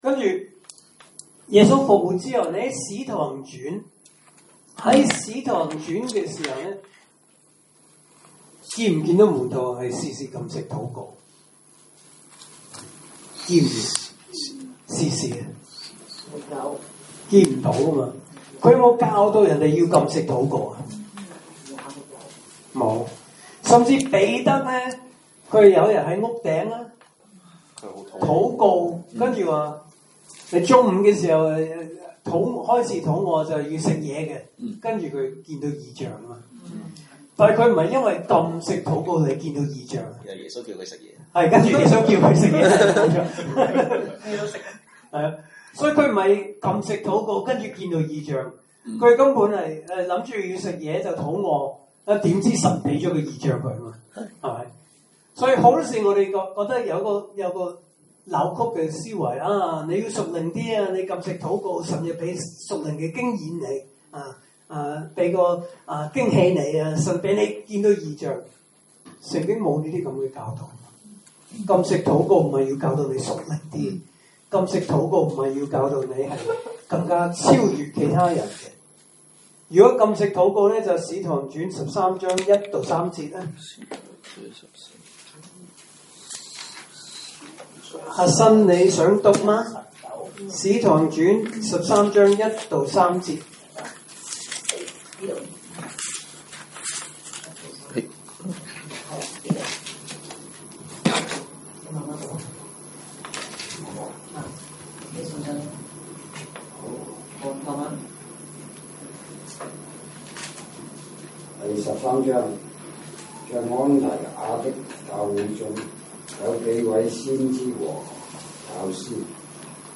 來自講道系列 "查經班：馬可福音"